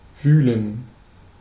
Ääntäminen
France: IPA: [kʁø.ze]